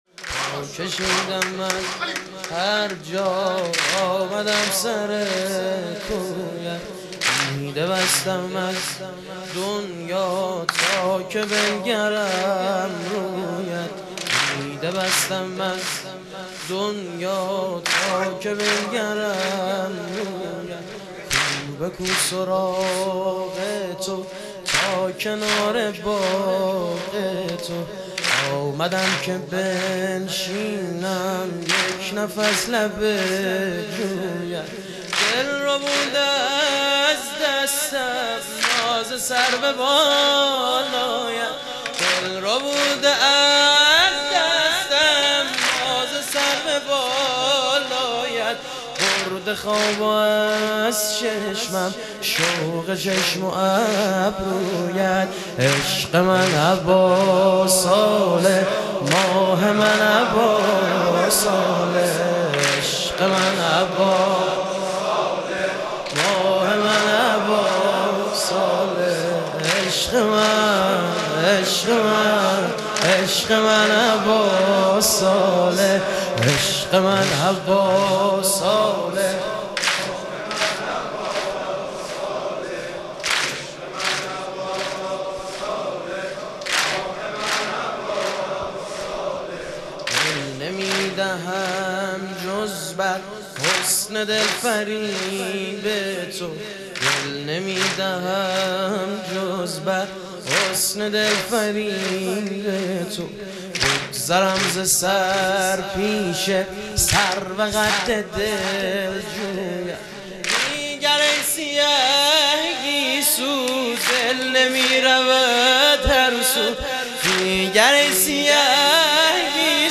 شهادت حضرت مسلم علیه السلام - واحد